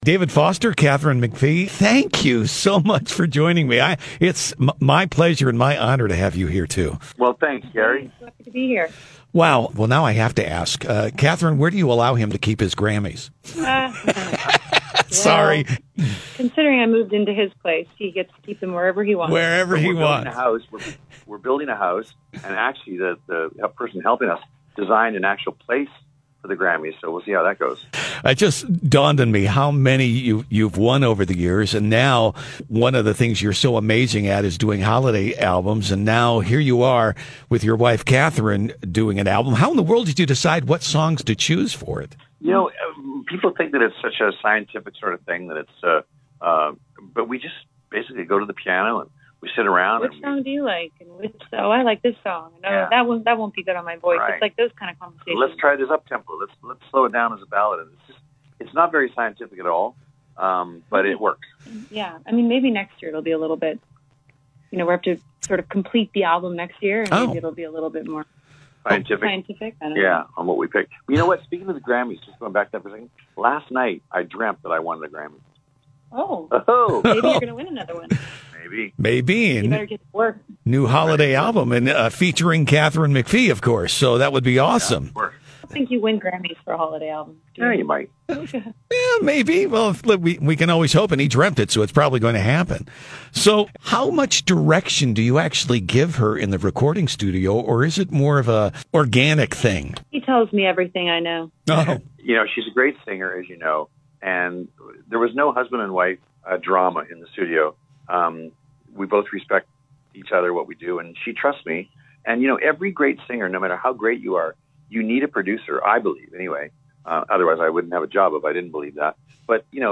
talked with 16 time Grammy Winner David Foster and his wife, Katherine McPhee about their new Christmas Album and the single, “Jingle Bell Rock” on the Mix Morning Show.